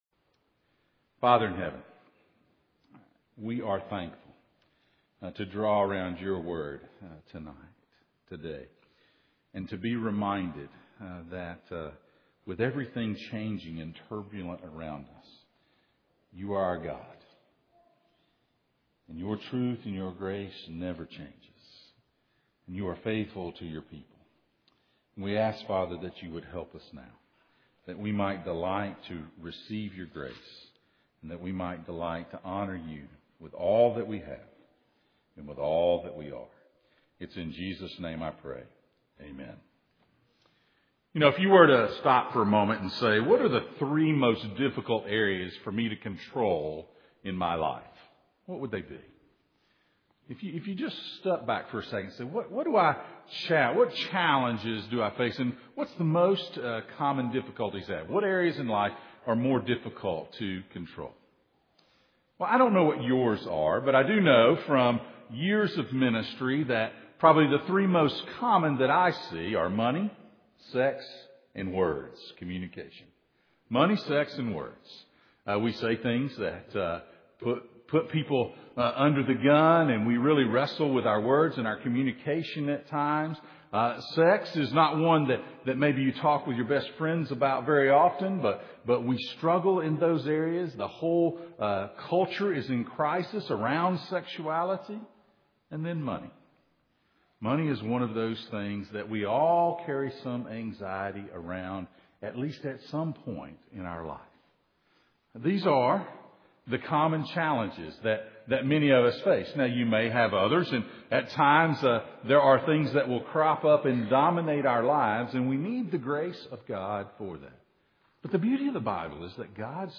Passage: Malachi 3:6-12 Service Type: Sunday Morning